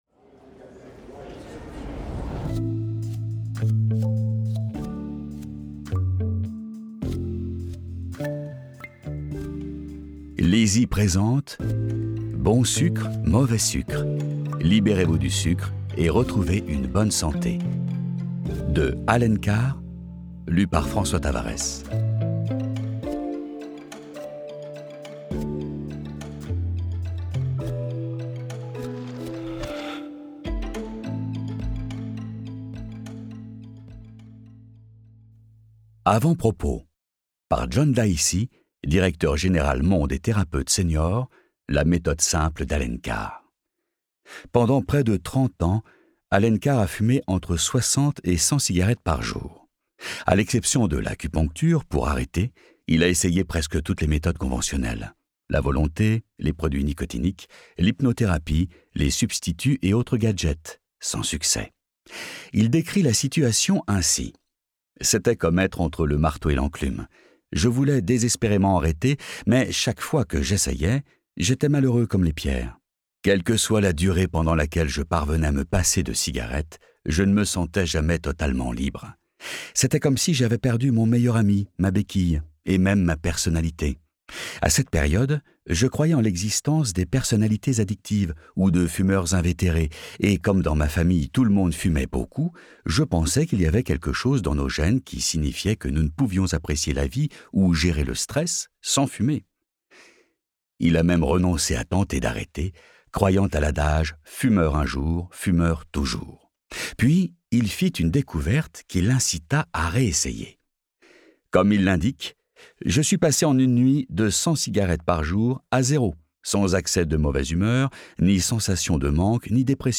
Ecoutez ce livre audio et pesez le poids que vous souhaitez pour le reste de votre vie !